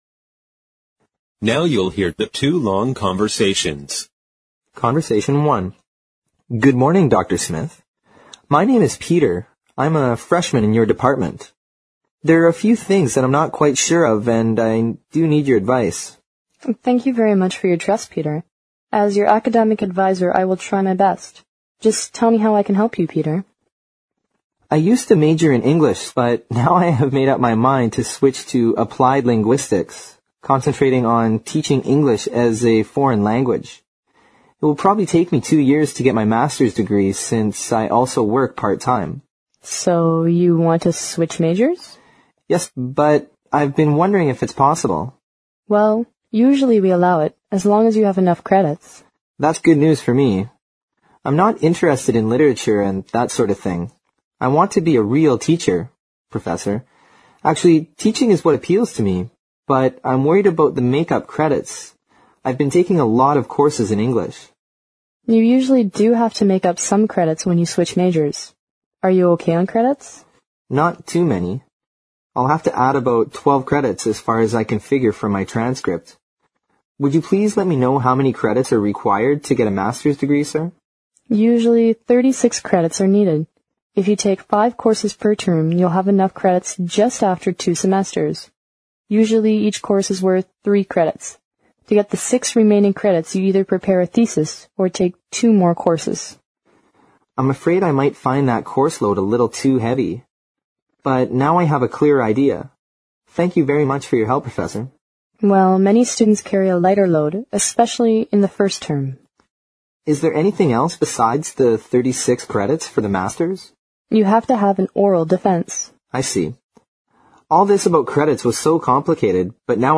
Now you'll hear the two long conversations.
Conversation One